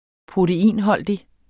Udtale [ -ˌhʌlˀdi ]